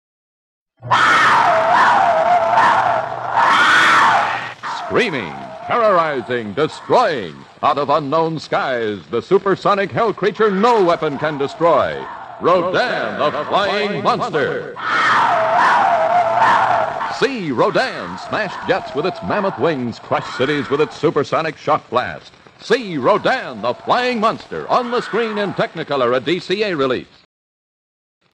The Flying Monster Radio Spots
20, 30, and 60 seconds radio spots for Rodan!